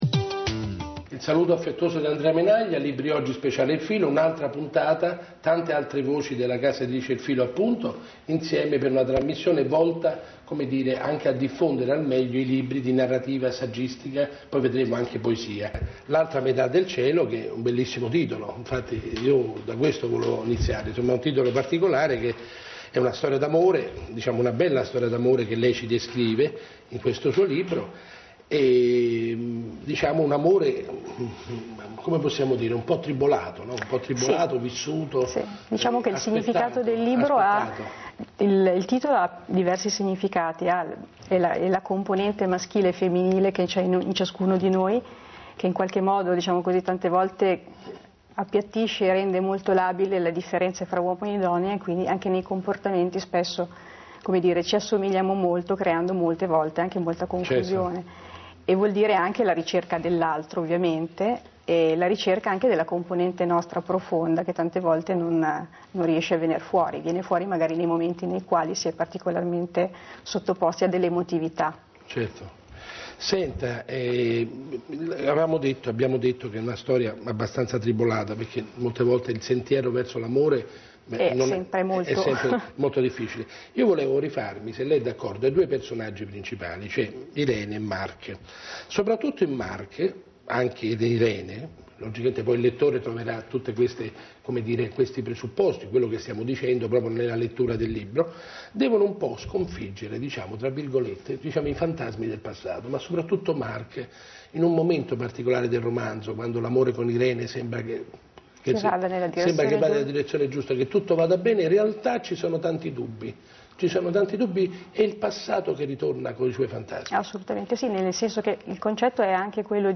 Intervista andata in onda il 18/03/2009 su Roma Sat durante la trasmissione Libri Oggi. In caso disponiate solo di una connessione lenta, cliccate qui per ascoltare l'intevista in versione solo audio a bassa qualità.
intervista_solo_audio.mp3